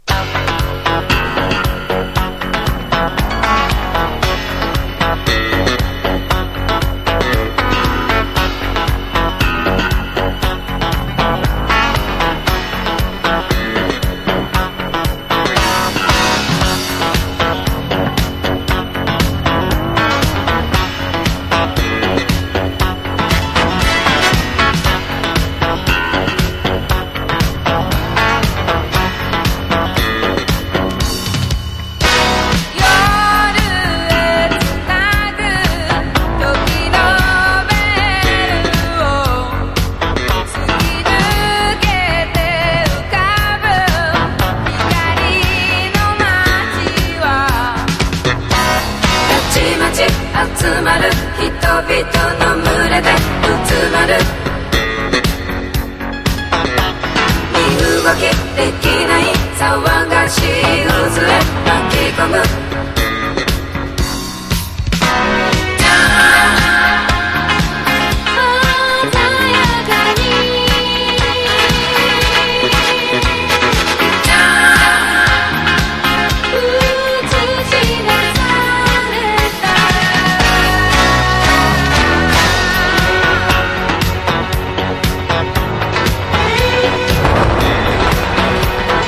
ダンサブルなファンクから濃厚なバラードまで名曲満載の人気盤。
# CITY POP / AOR# 和モノ